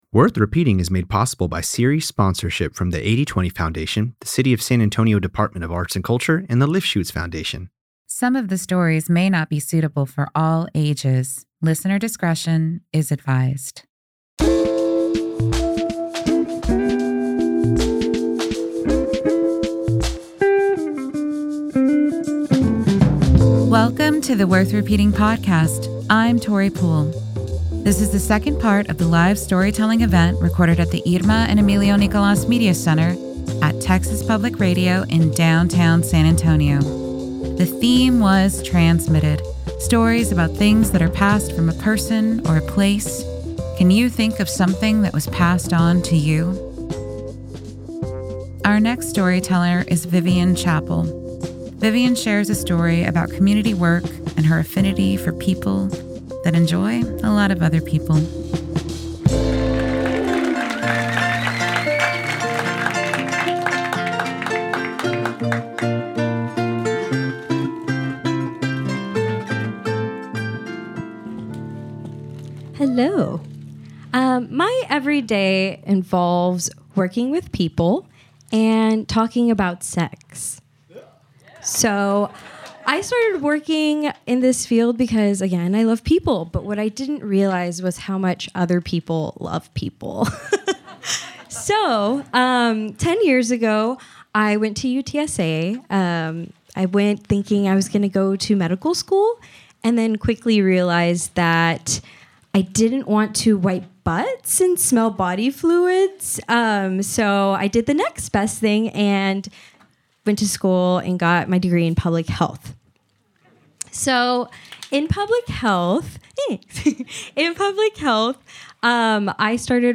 In this episode of the Worth Repeating podcast, storytellers share stories on the theme, Transmitted.
Play Rate Listened List Bookmark Get this podcast via API From The Podcast Real stories told by your neighbors and friends in San Antonio.